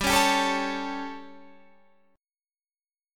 G Suspended 2nd Flat 5th